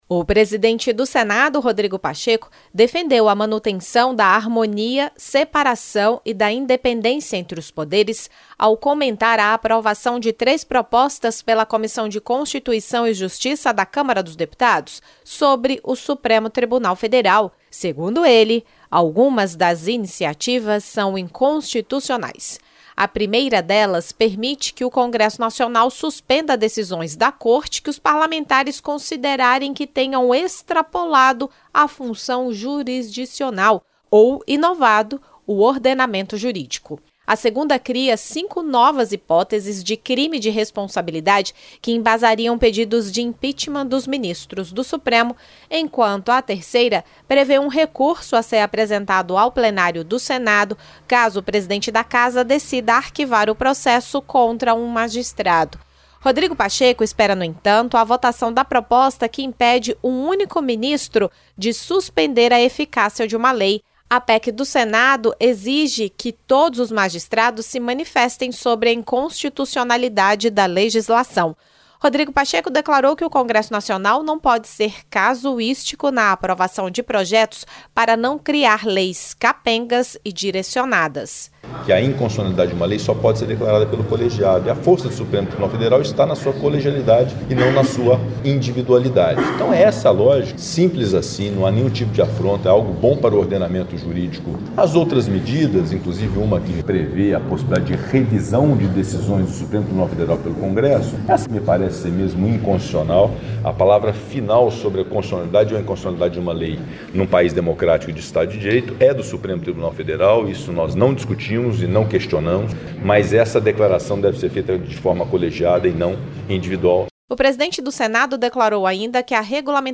O presidente do Senado, Rodrigo Pacheco, alertou para a inconstitucionalidade de propostas aprovadas pelos deputados que tratam da atuação do Supremo Tribunal Federal.